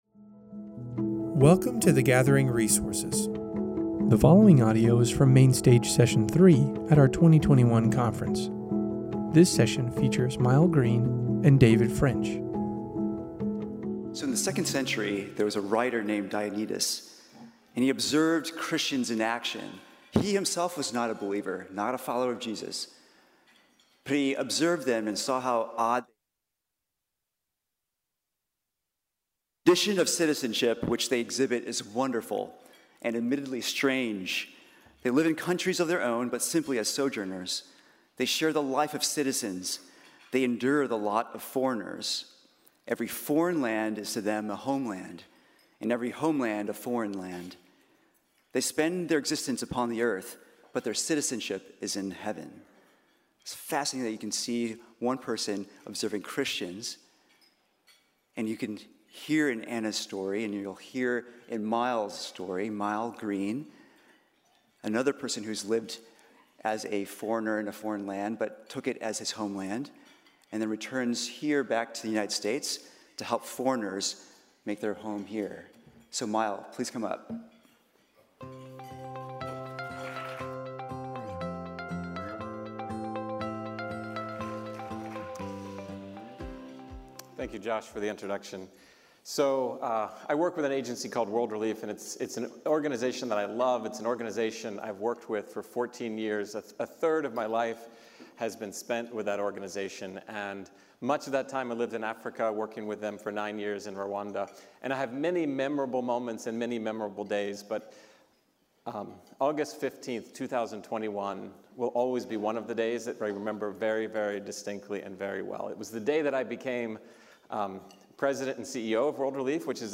The following audio is from Main Stage Session 3 at our 2021 conference.